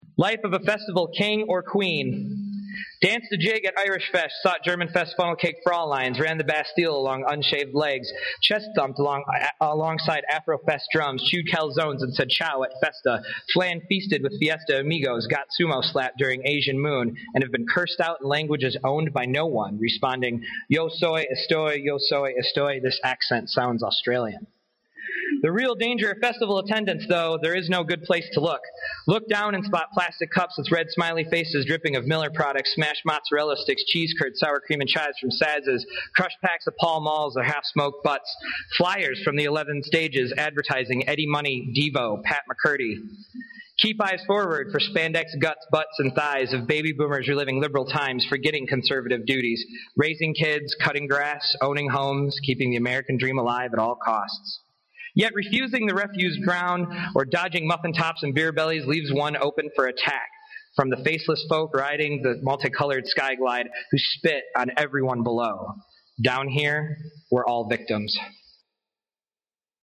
audio these three clips are from my MFA Thesis reading on Friday April 15th, 2011...I read fast, and you�ll find the poems on the text page if you can�t keep up...